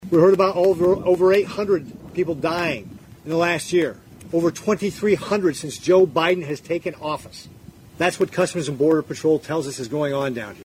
RICKETTS SAYS THE OPEN BORDER POLICY HAS ALSO RESULTED IN THE DEATHS OF MANY IMMIGRANTS WHO ARE PUTTING THEMSELVES IN DANGER: